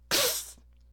pain2.ogg